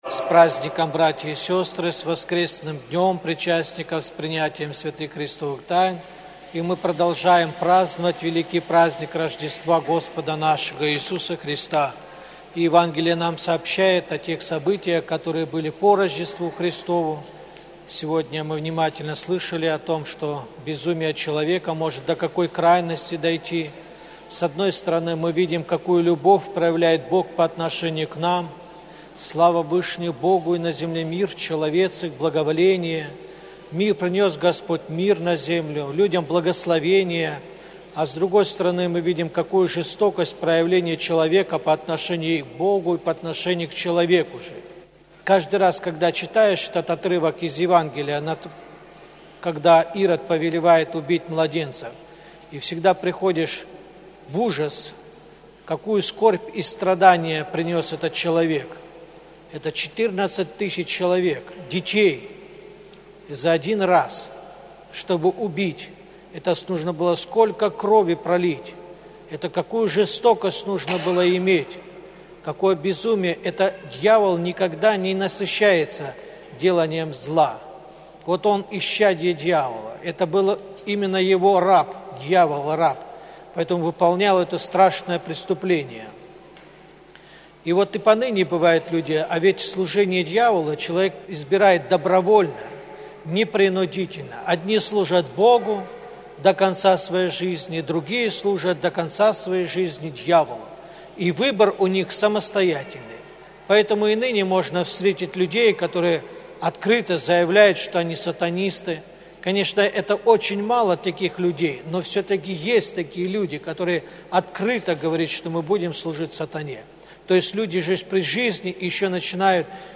Проповедь на Литургии читает епископ Балтийский Серафим